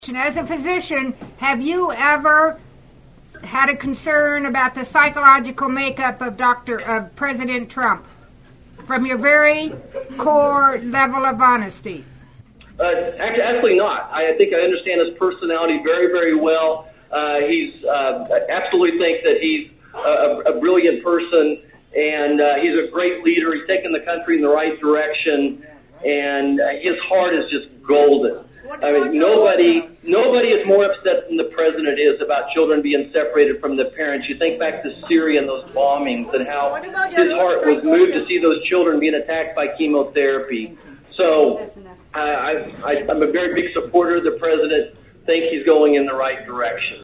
Kansas Congressman Dr. Roger Marshall participated in a teleconference with a group on constituents concerned about immigration issues.
Over a dozen people gathered inside the First District Congressman’s Office in Salina.